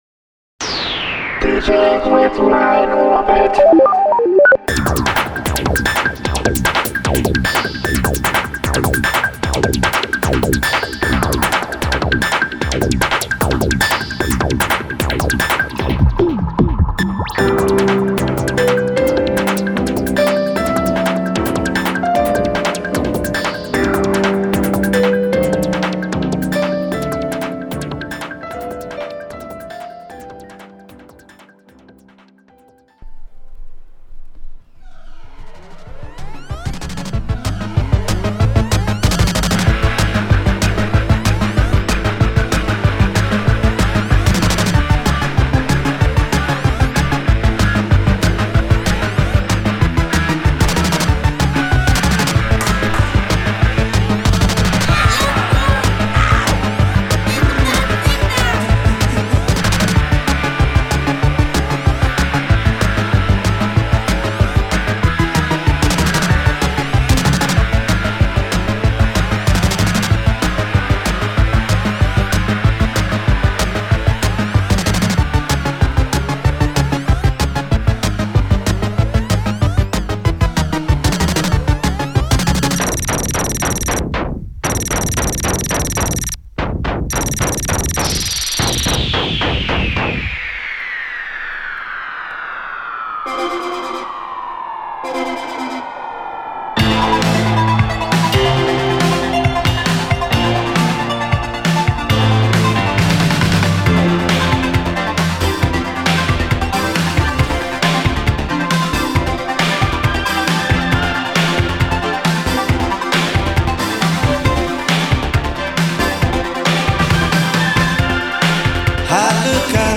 Today’s TMBR is Japanese Techno Pop Special.